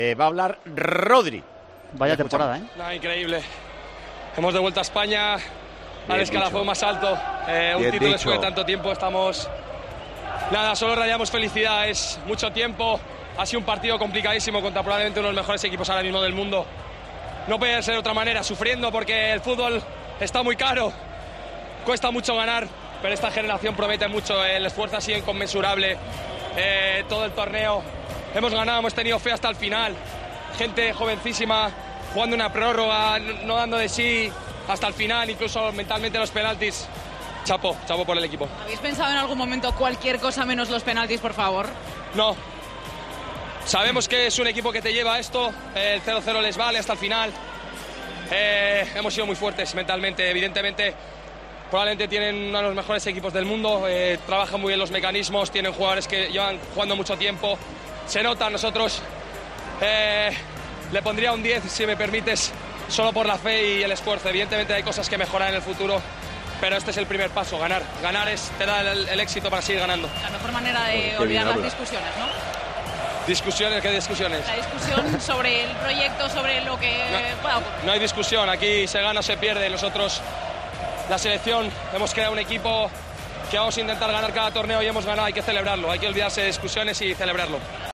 El centrocampista de la selección habló para Televisión Española tras vencer en la tanda de penaltis a la selección de Croacia en la final de la Liga de Naciones.